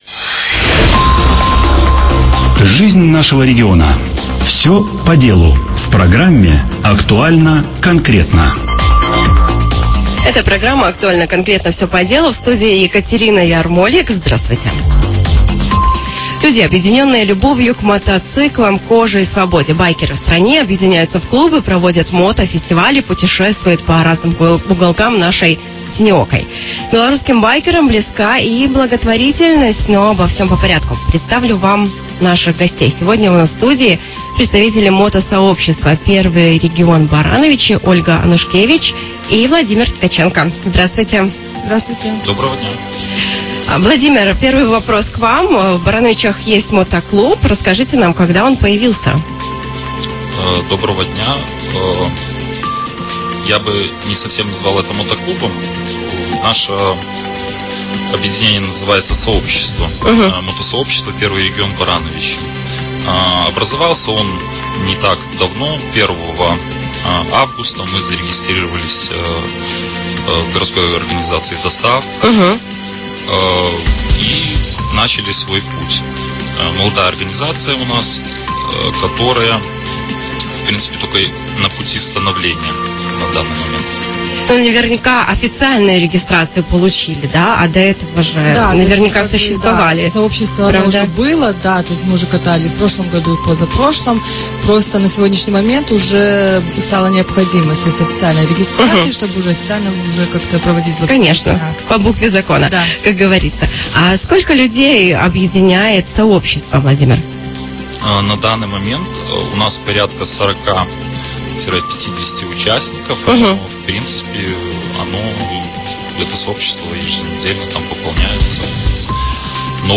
У нас в студии — представители мотосообщества